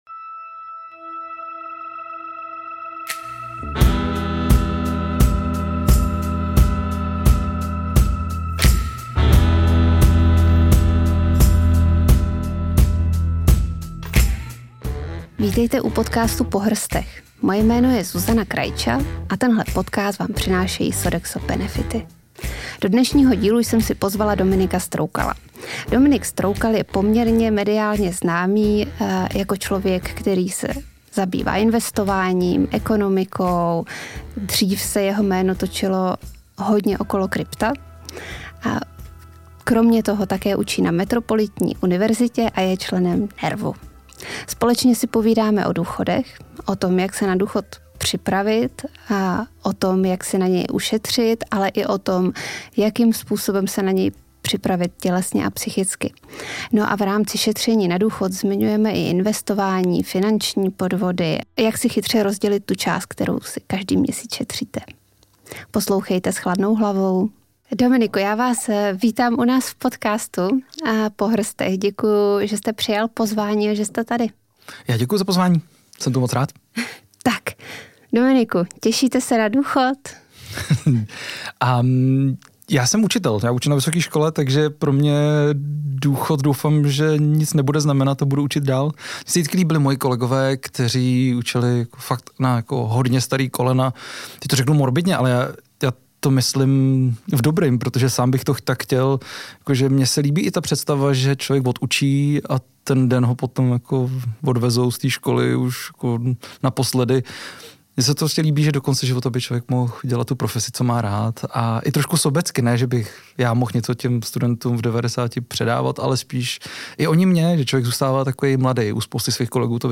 V rozhovoru probíráme téma, které se při troše štěstí bude týkat nás všech – důchod.